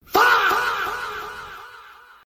крики